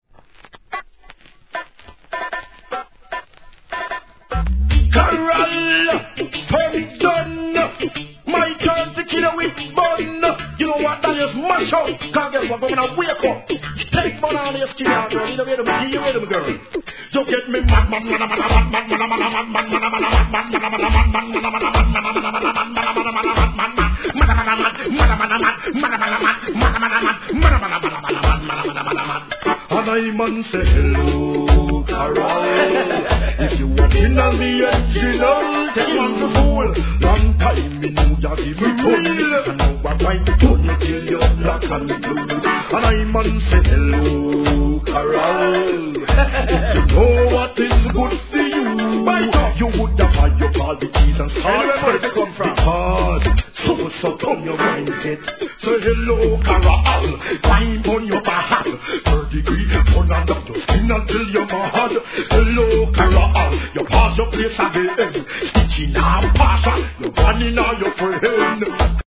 REGGAE
強烈なイントロで大人気！！